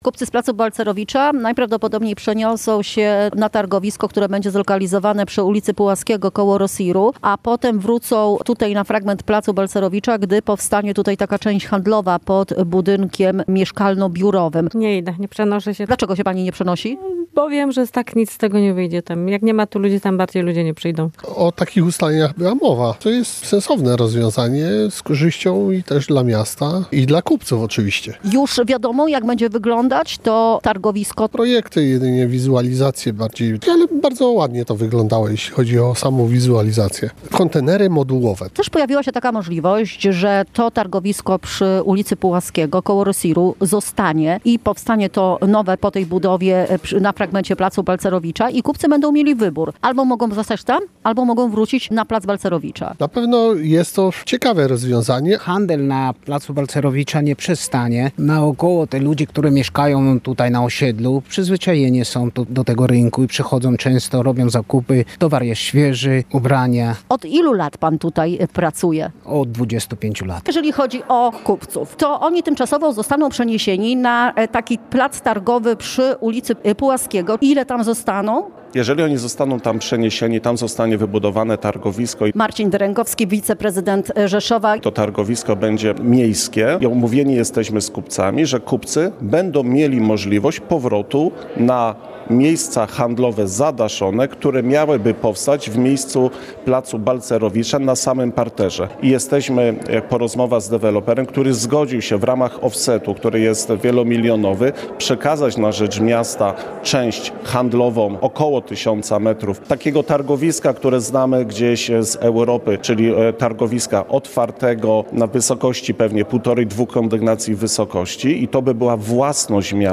Kupcy z Placu Balcerowicza w Rzeszowie dostaną wybór • Relacje reporterskie • Polskie Radio Rzeszów